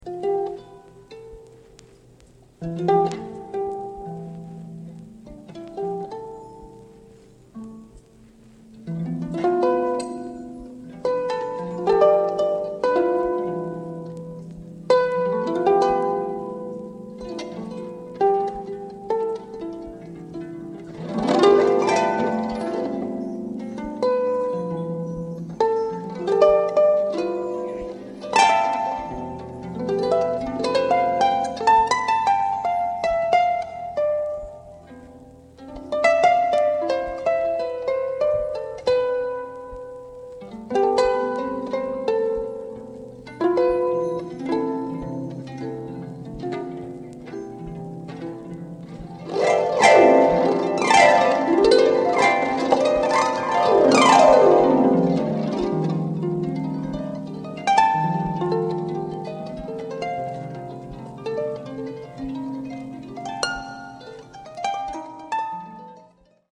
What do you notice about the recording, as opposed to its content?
performing at the Palace of Culture in 1987, Warsaw, Poland